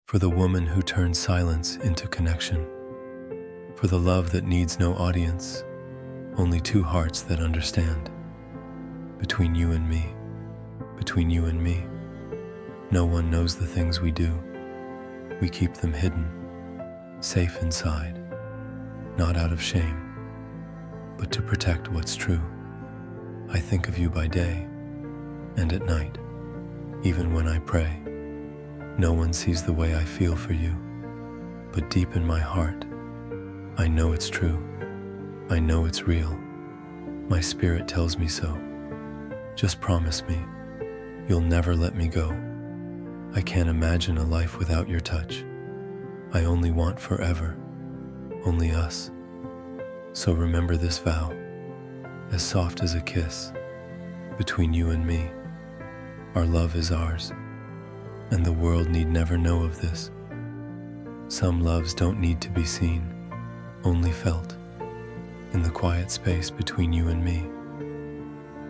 Between-You-and-Me-–-Love-Poem-Spoken-Word.mp3